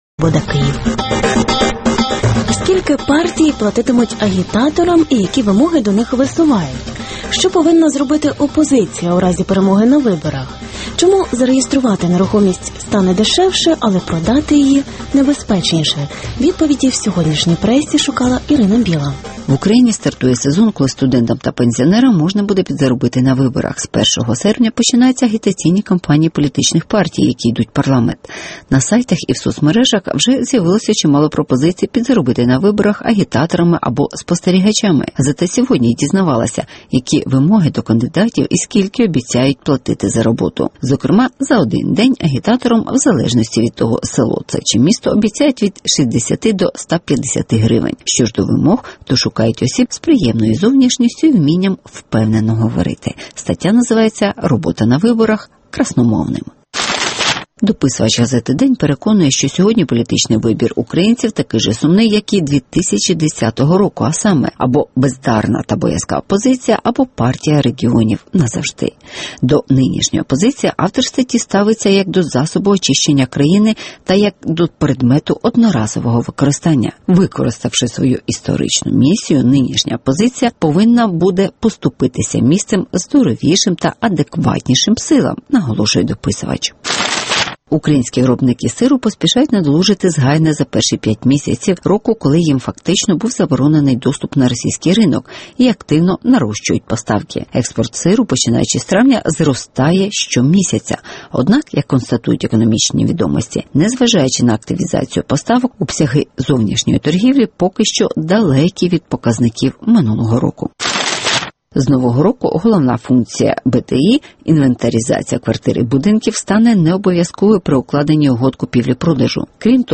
Партії шукають красномовних агітаторів (огляд преси)